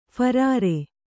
♪ pharāre